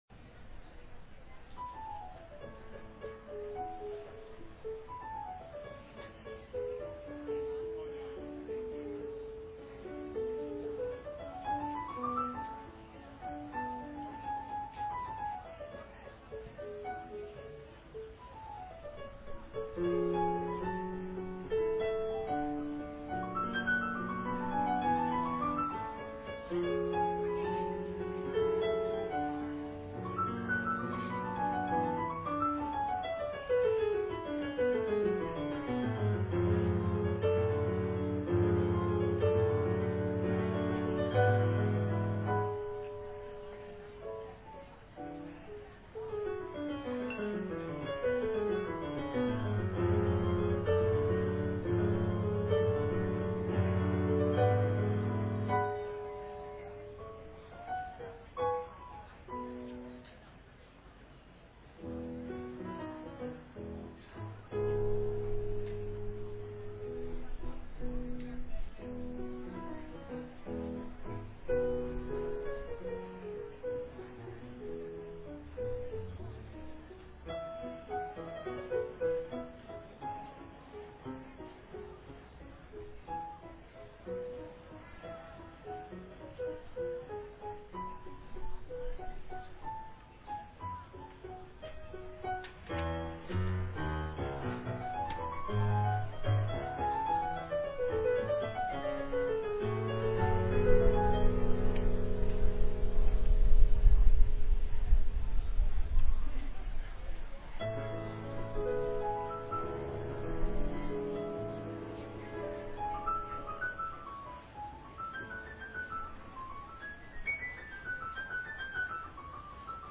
Classical Music Festival
Part of the Arts and Wine Festival
Courthouse Park, Cortland, NY USA
piano